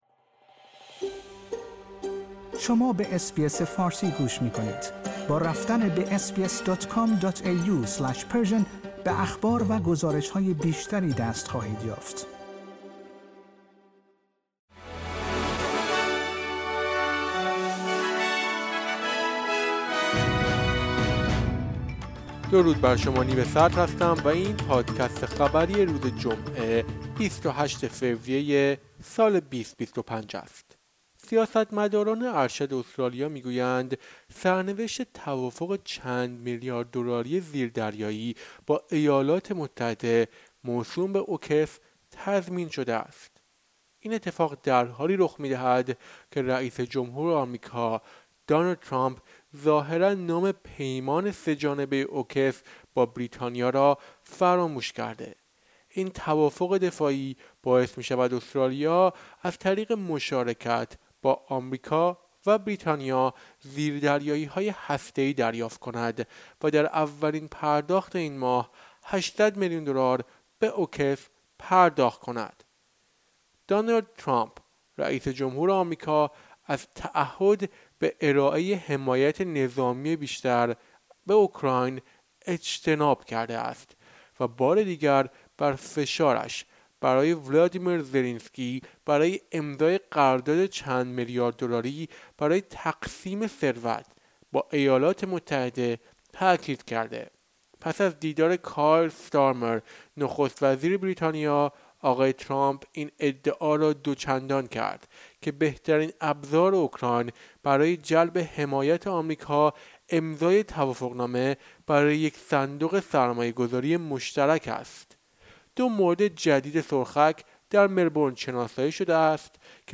در این پادکست خبری مهمترین اخبار استرالیا در روز جمعه ۲۸ فوریه ۲۰۲۵ ارائه شده است.